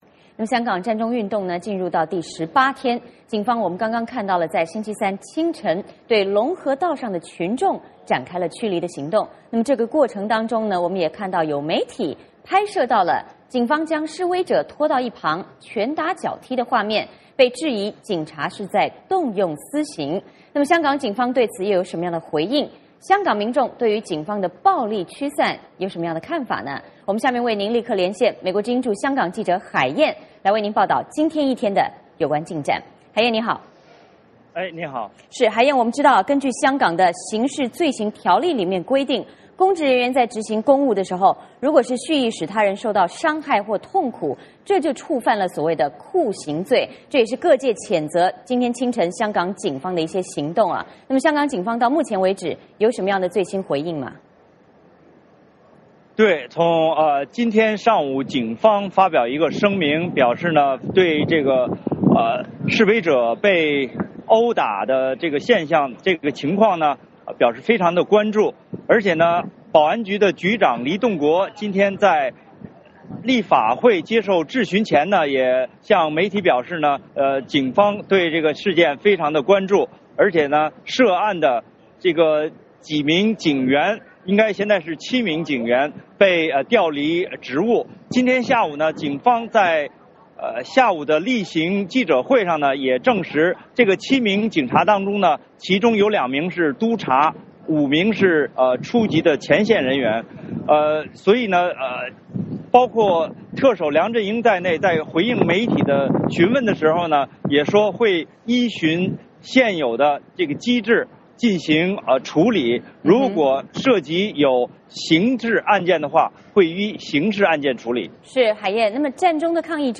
VOA连线：港警清晨进行驱散，多人被捕，冲突激烈